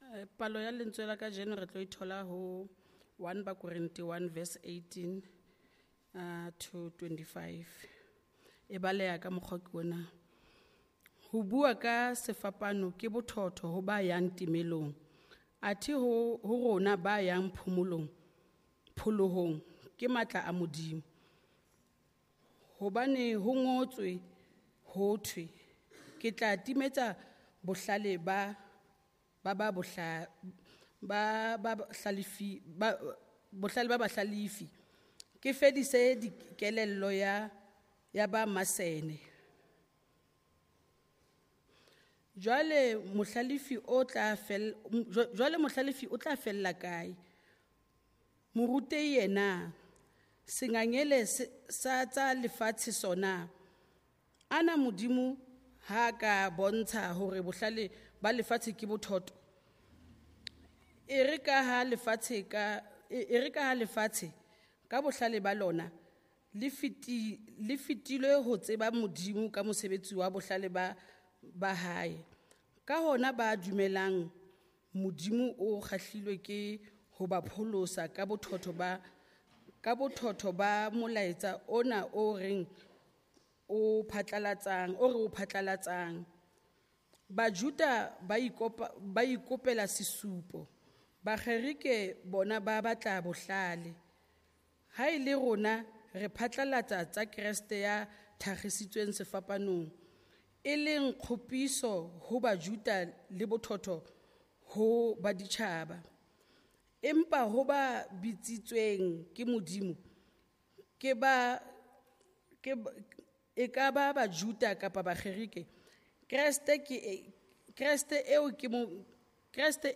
11:30am Kopanang Service from Trinity Methodist Church, Linden, Johannesburg